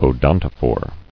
[o·don·to·phore]